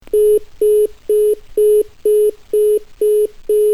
SFX电话被挂断后忙音音效下载
SFX音效